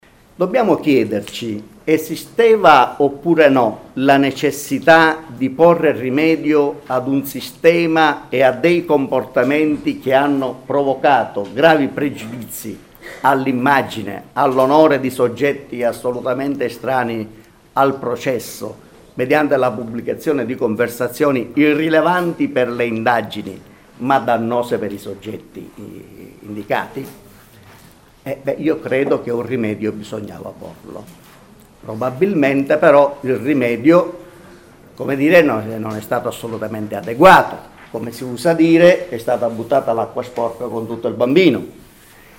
Il procuratore capo di Bologna Roberto Alfonso è intervenuto al convegno organizzato da Magistratura Democratica, la corrente tradizionalmente collocata a sinistra della magistratura, criticando il disegno di legge sulle intercettazioni sul quale il Governo si sta preparando a porre la fiducia al Senato.
Ascolta il procuratore